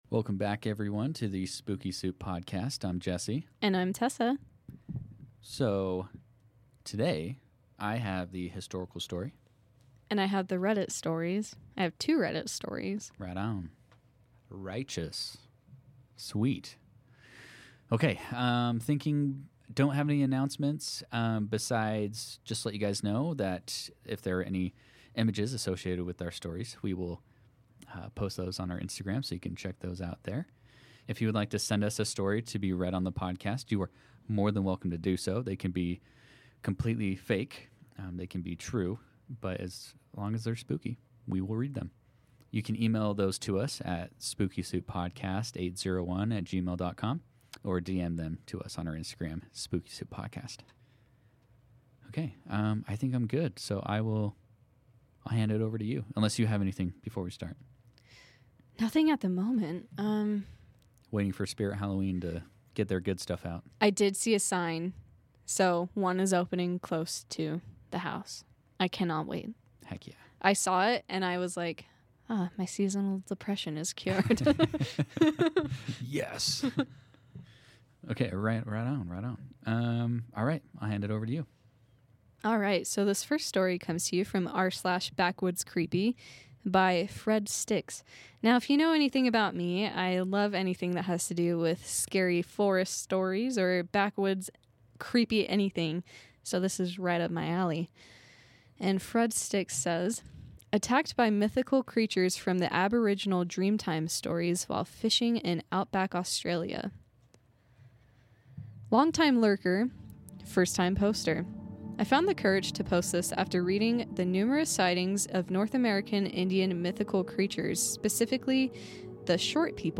Just two siblings obsessed with the weird, strange and curious.